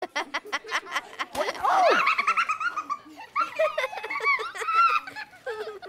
Kategorien Lustige